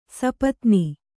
♪ sapatni